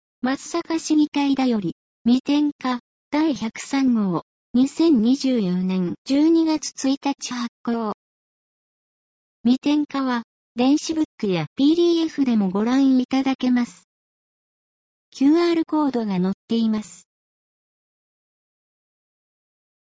なお、この音声は「音訳グループまつさか＜外部リンク＞」の皆さんの協力で作成しています。